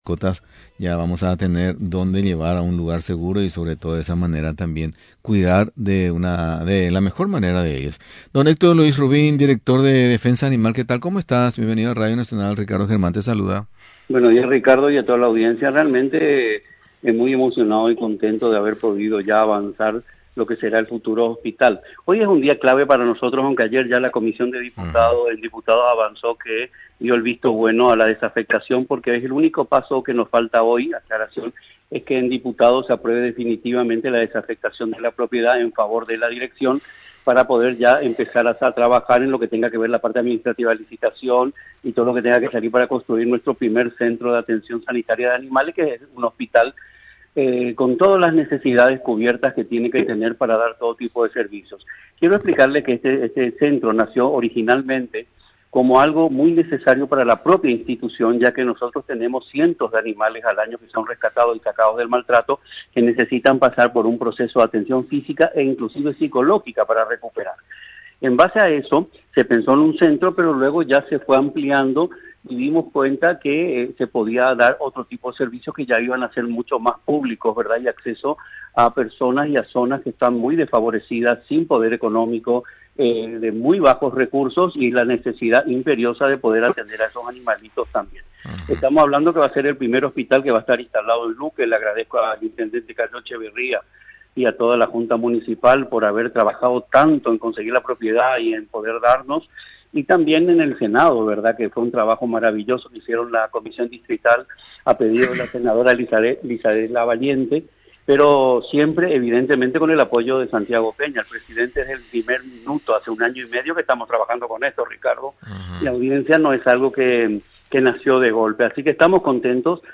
En conversación con Radio Nacional del Paraguay, el director de Defensa Animal, Héctor Luis Rubín, señaló que este dictamen favorable es un paso fundamental para avanzar con las gestiones administrativas y dar inicio al proceso licitatorio de la construcción del centro sanitario.